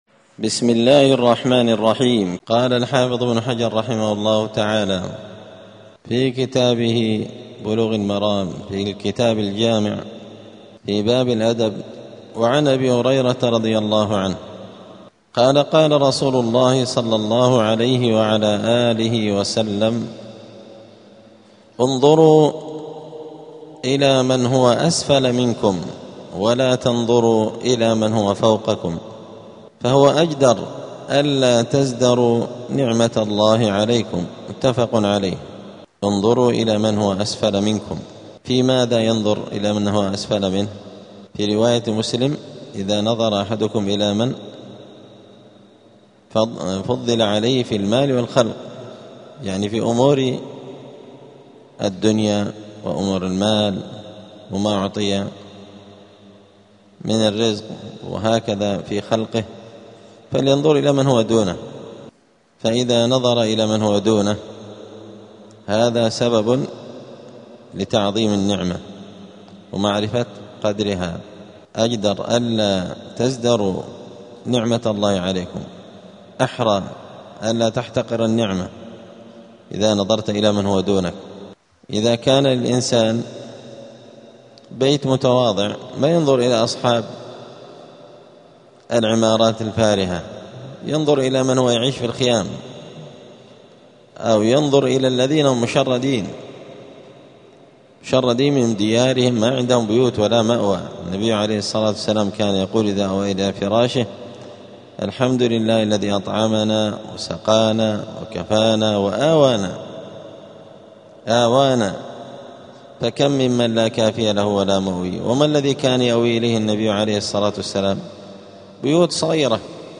*الدرس الثالث (3) {باب اﻟﺒﺮ ﻭﺣﺴﻦ اﻟﺨﻠﻖ}*
دار الحديث السلفية بمسجد الفرقان قشن المهرة اليمن